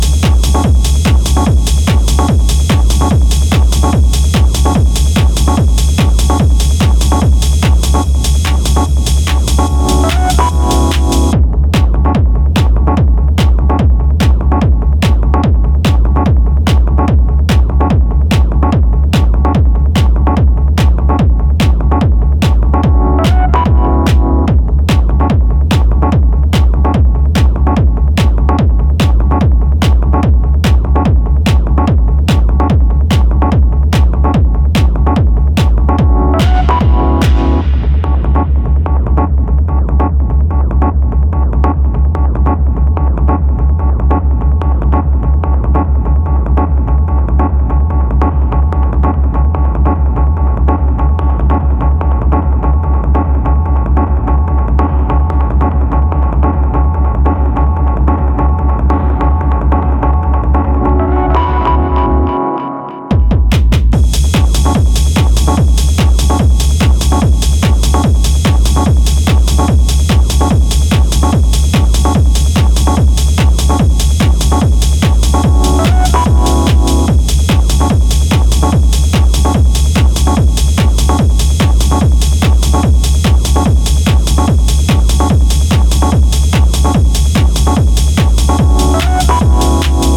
a fun and warm energy
techno